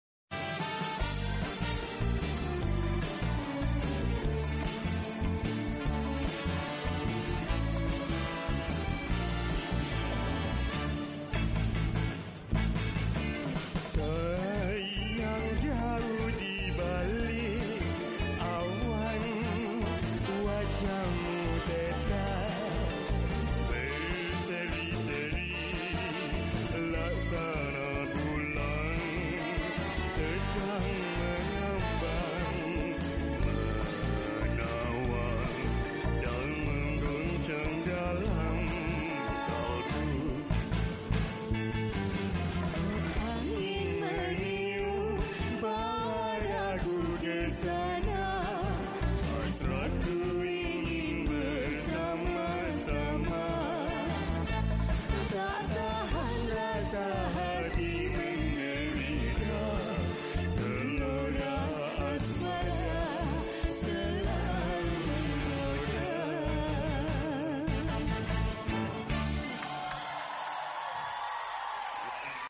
AUDITORIUM SERI ANGKASA, ANGKASAPURI, KOTA MEDIA